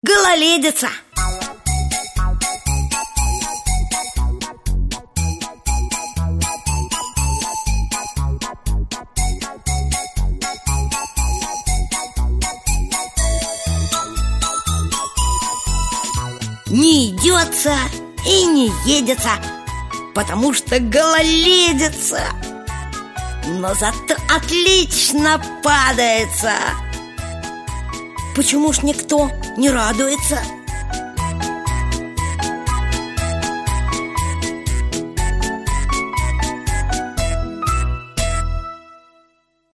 На данной странице вы можете слушать онлайн бесплатно и скачать аудиокнигу "Гололедица" писателя Валентин Берестов.